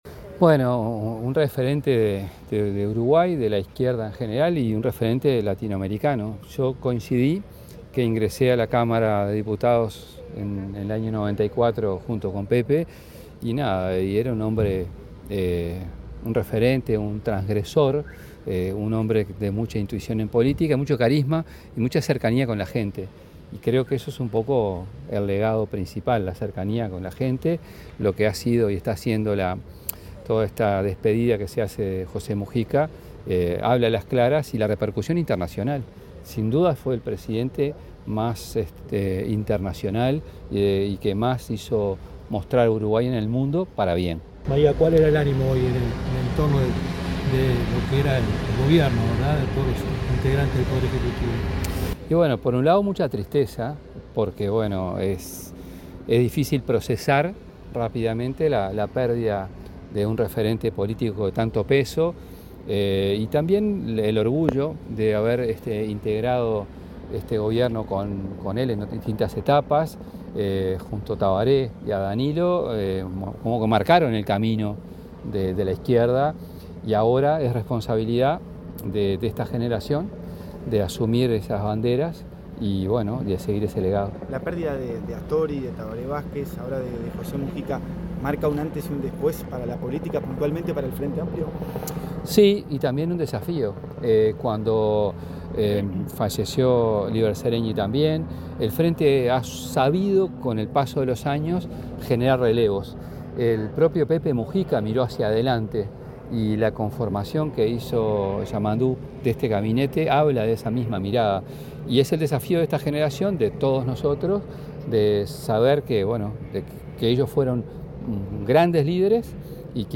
El ministro de Educación y Cultura, José Carlos Mahía, dialogó con la prensa en el Palacio Legislativo, acerca de la figura del exmandatario José